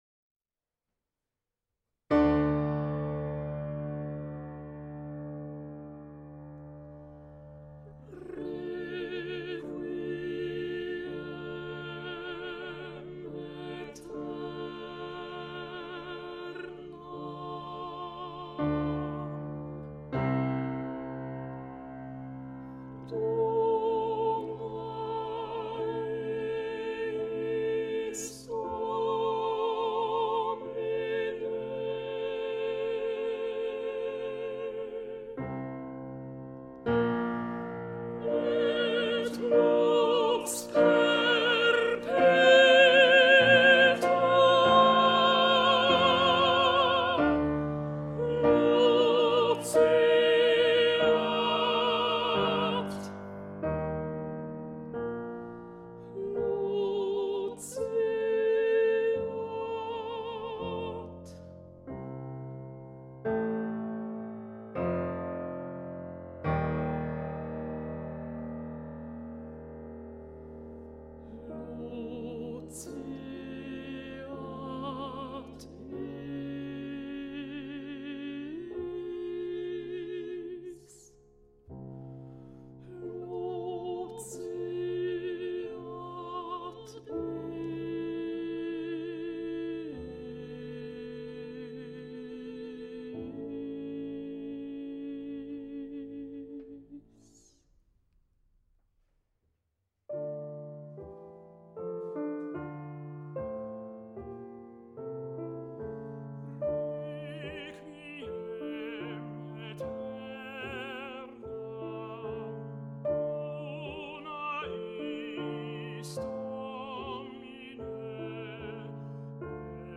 introit et kyrie alto amplifie